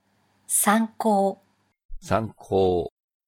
Prononciation-de-sanko.mp3